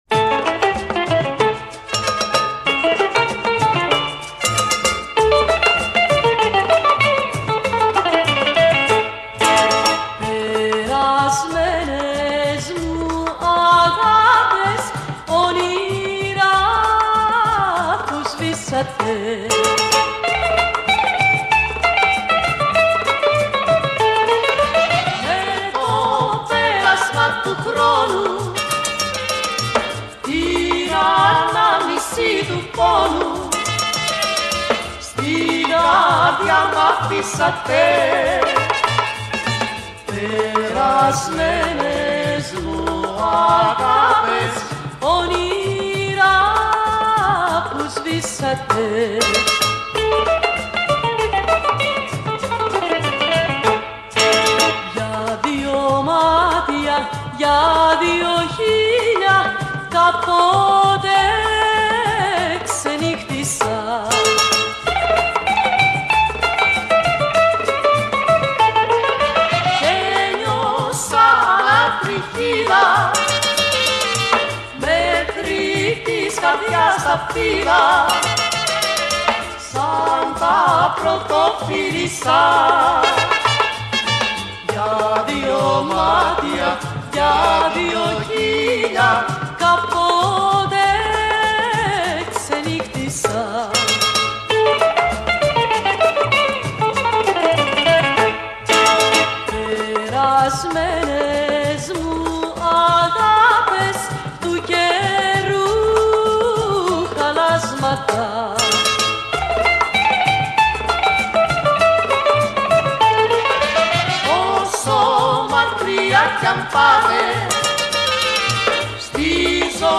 Ιδιαίτερη θέση στο αφιέρωμα έχουν τα τραγούδια της, που συνδέθηκαν με τις μεγάλες πληγές της ζωής.